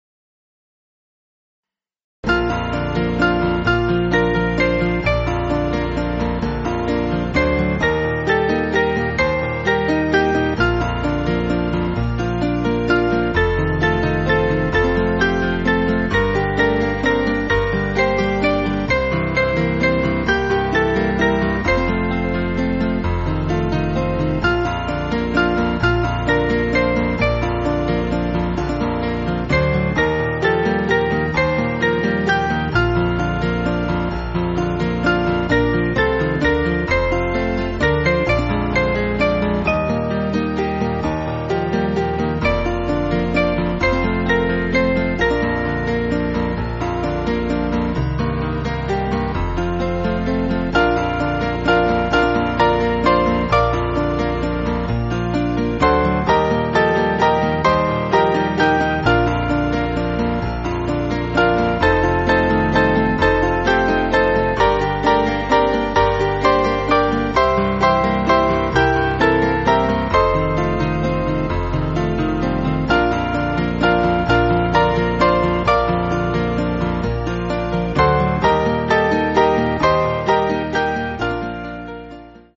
Small Band
(CM)   2/Bb